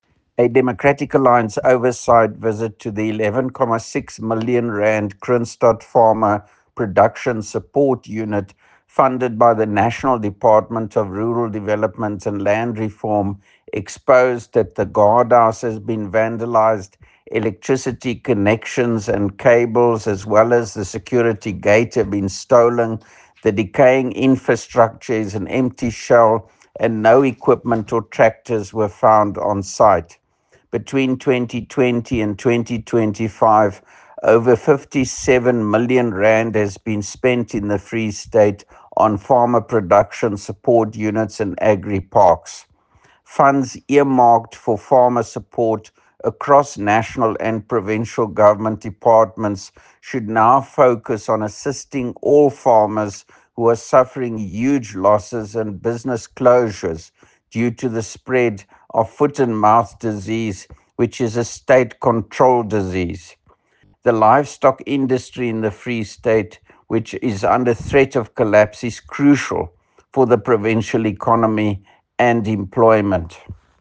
Afrikaans soundbites by Roy Jankielsohn, MPL and